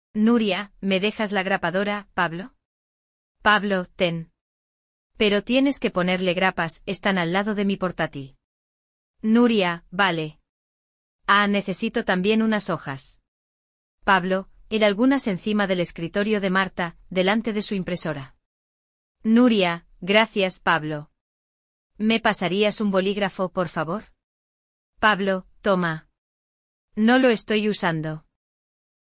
Diálogo